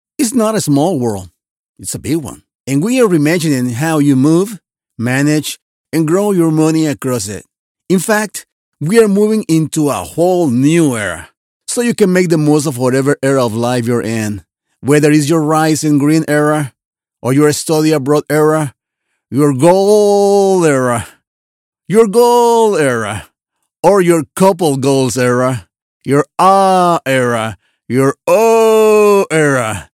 Bilingual clear voice, dynamic, positive, emotive, promotional, corporative, friendly.
Accented English - Explainer
English with a Spanish adjustable accent
My PRO STUDIO is equipped with SOURCE CONNECT STANDARD (available at an extra rate), PRO TOOLS, AKG 414 B/ULS mic, and AVID MBOX STUDIO.